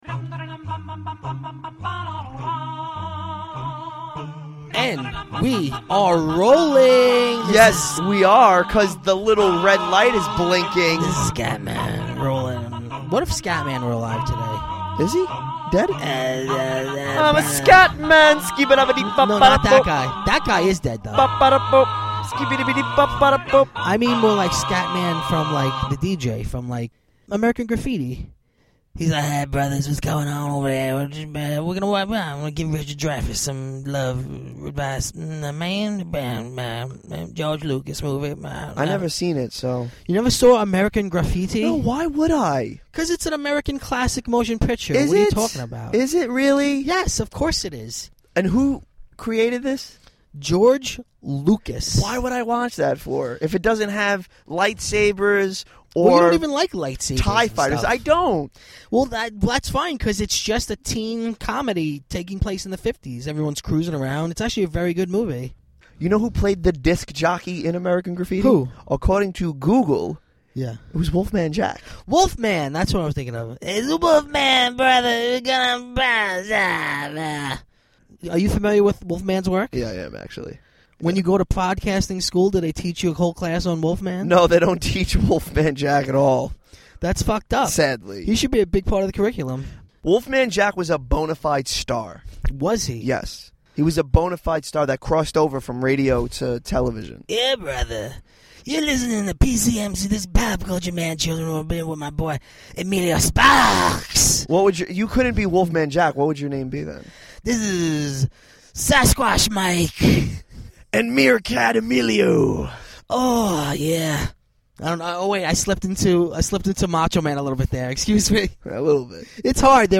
Plus a lot of obscure bad impressions of celebrities no one remembers!